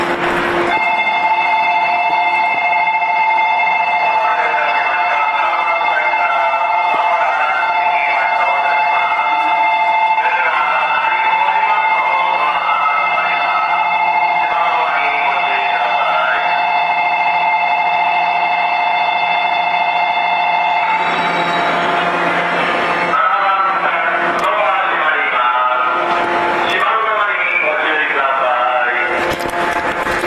ベル